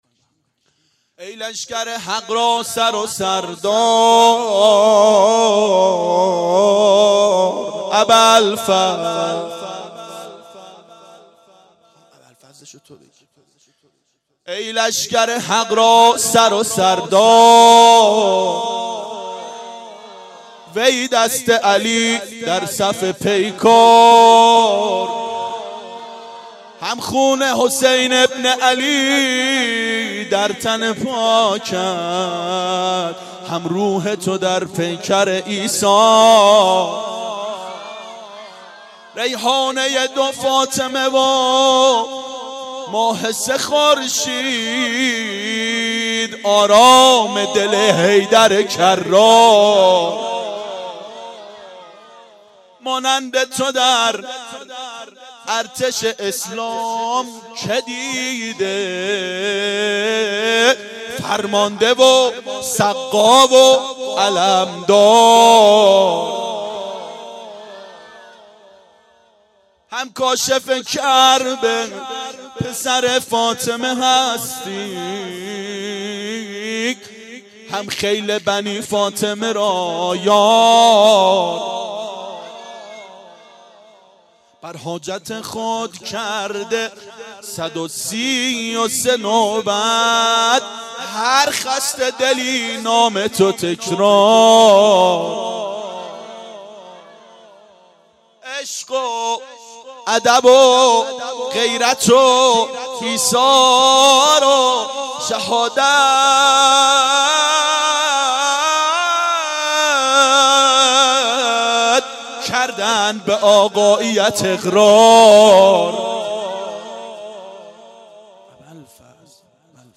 ای لشکر حق را سر و سردار / مدح
• میلاد امام حسین و حضرت اباالفضل علیهماالسلام 93 عاشقان حضرت اباالفضل علیه السلام منارجنبان